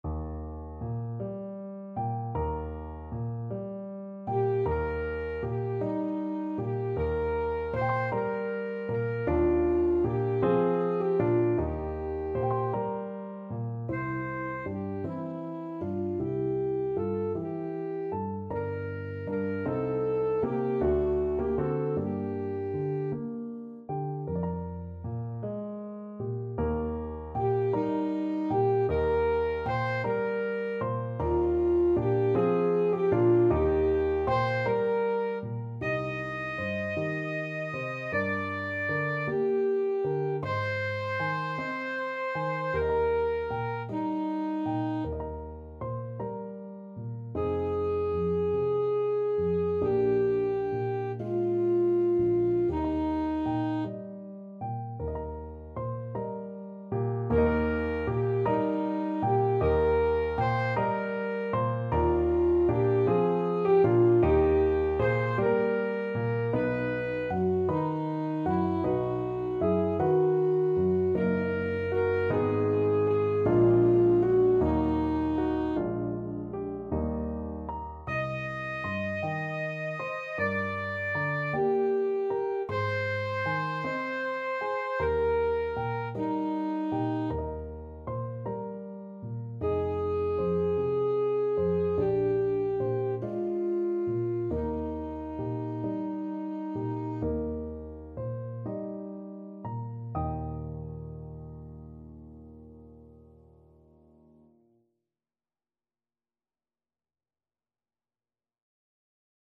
Christmas
Alto Saxophone
6/8 (View more 6/8 Music)
~. = 52 Allegretto
Eb5-Eb6
Classical (View more Classical Saxophone Music)